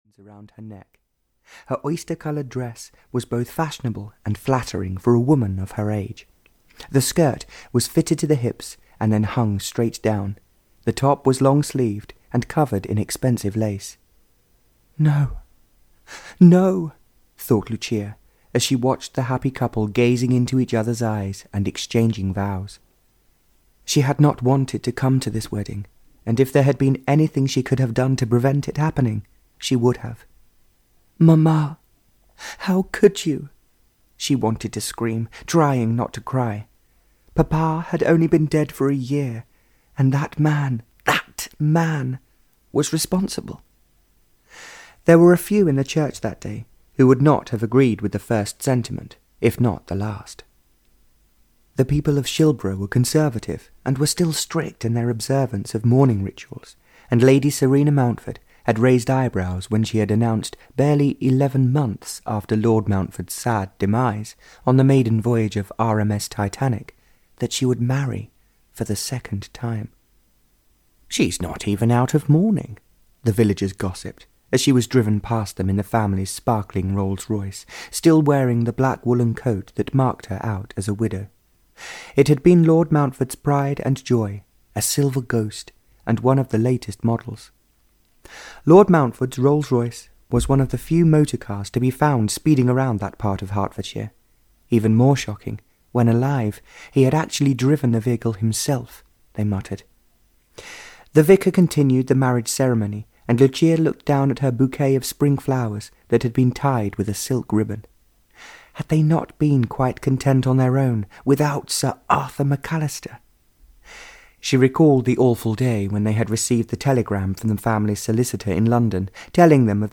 A Dream Come True (EN) audiokniha
Ukázka z knihy